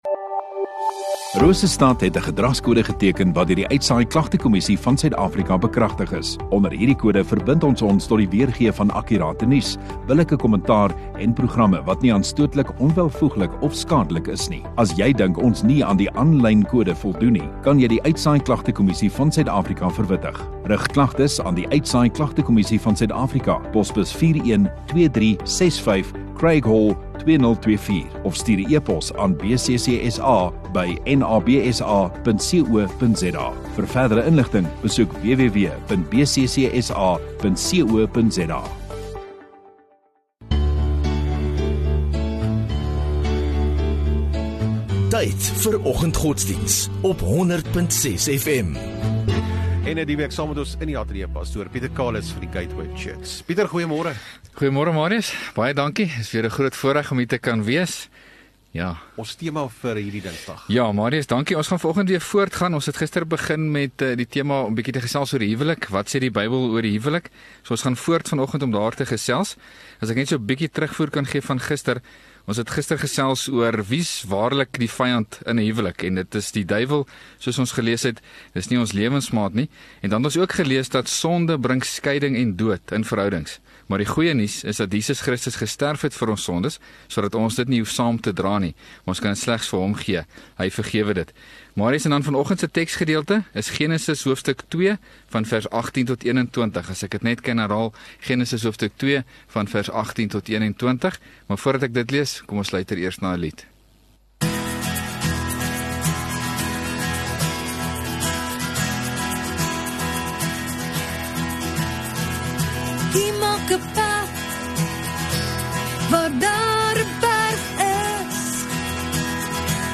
12 Nov Dinsdag Oggenddiens